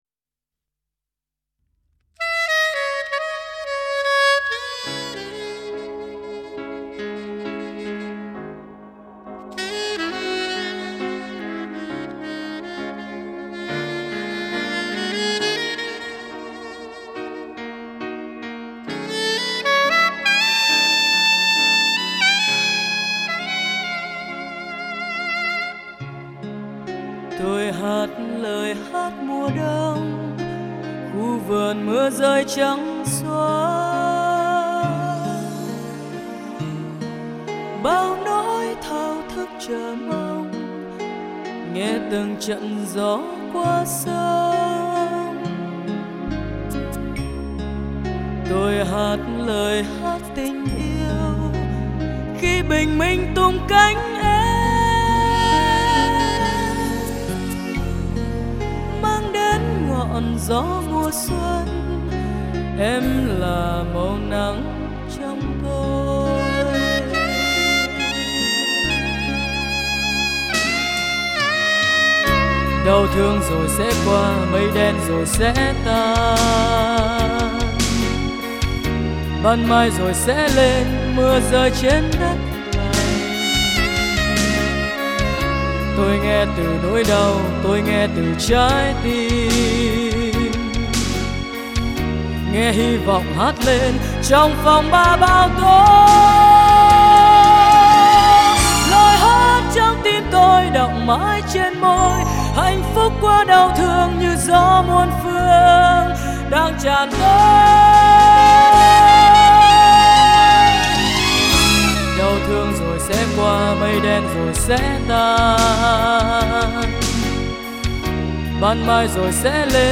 ca khúc